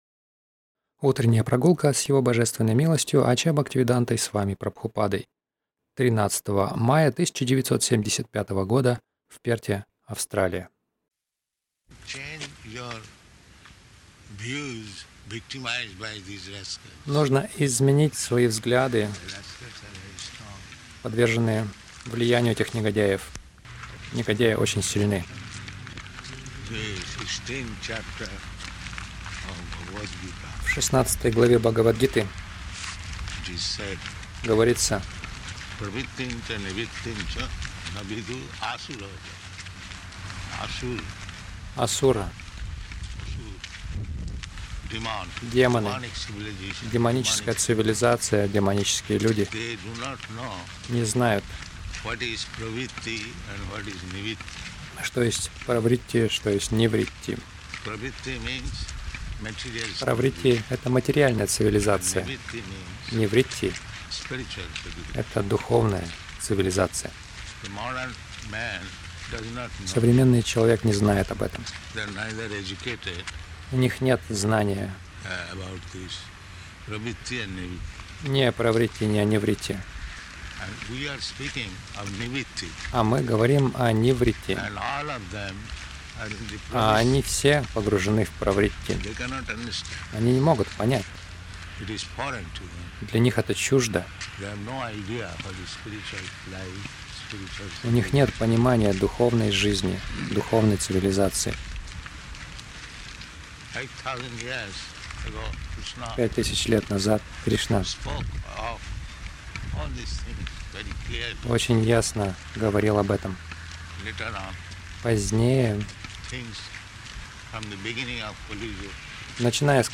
Милость Прабхупады Аудиолекции и книги 13.05.1975 Утренние Прогулки | Перт Утренние прогулки — Правритти и Нивритти Загрузка...